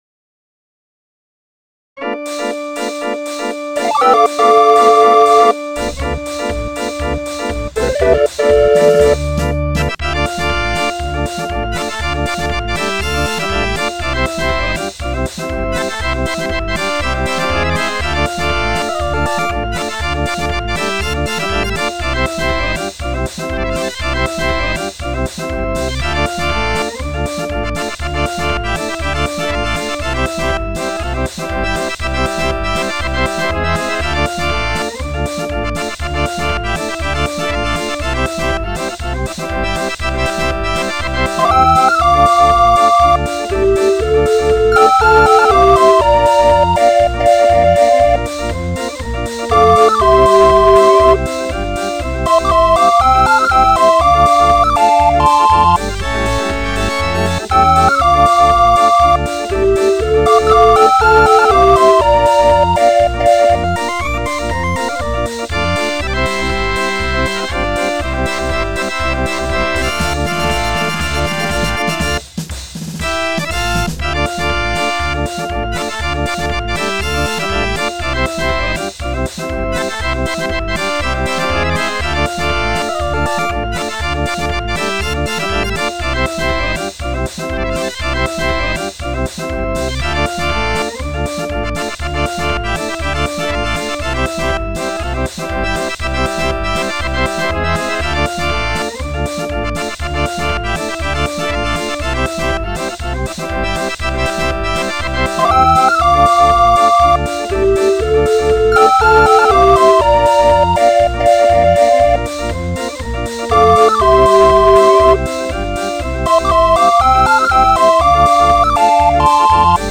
Musikrollen, Notenbücher und Zubehör für Drehorgeln.